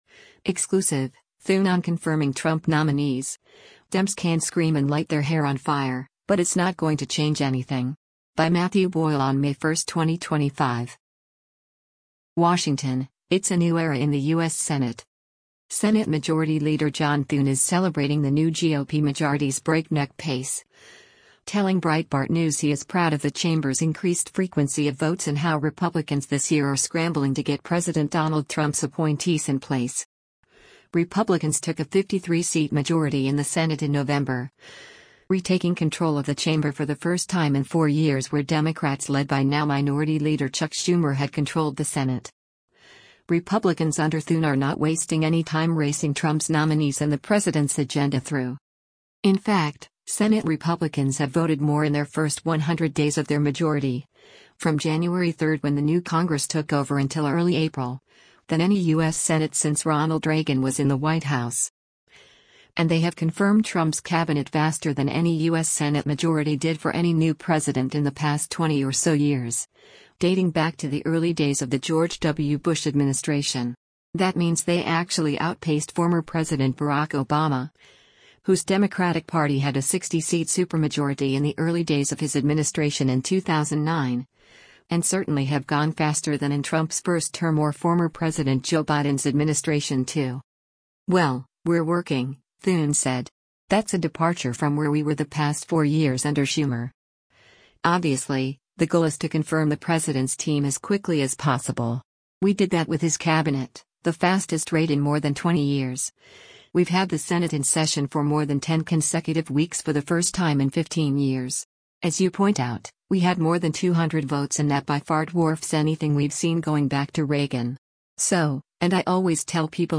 Thune told Breitbart News later in this interview that he is targeting July 4 for getting the reconciliation bill to the president’s desk — that clip was the first-published from this sit-down.